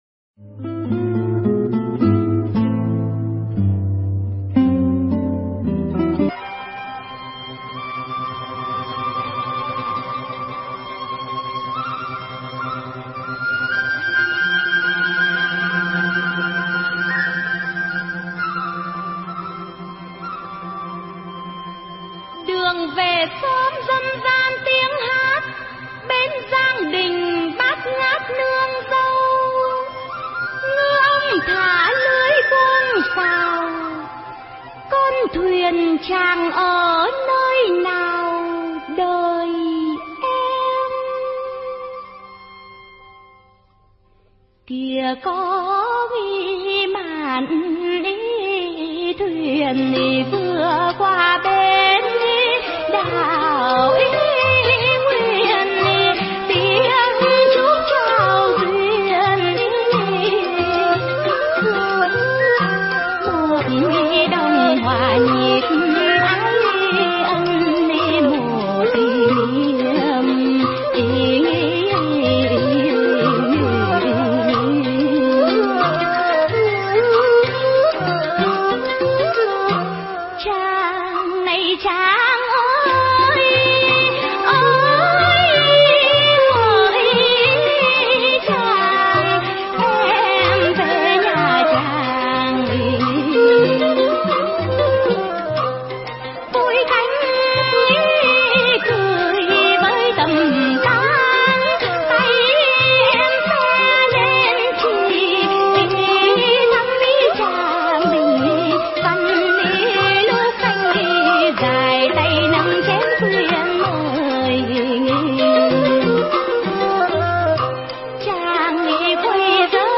Tuyển Chọn Dân Ca Ví Dặm Mp3 Cổ